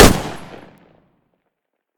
defender-shot-1.ogg